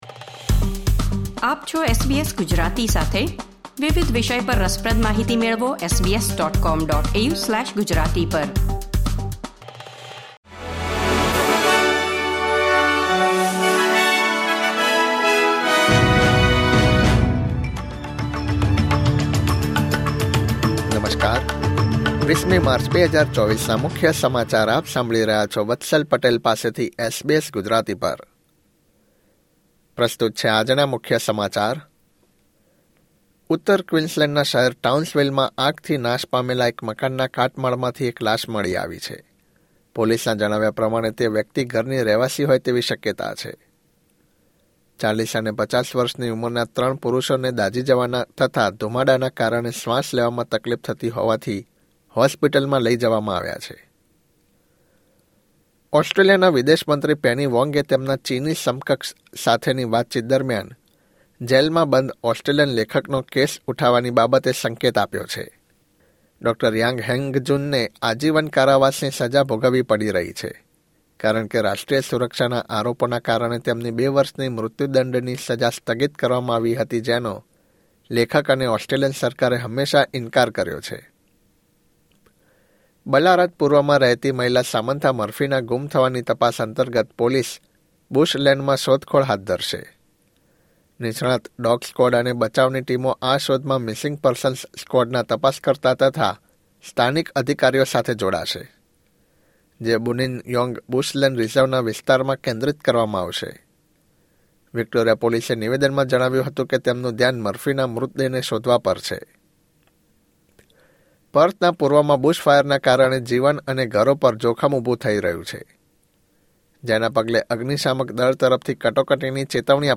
SBS Gujarati News Bulletin 20 March 2024